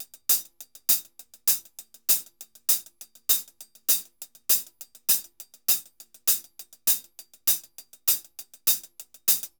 HH_Baion 100_2.wav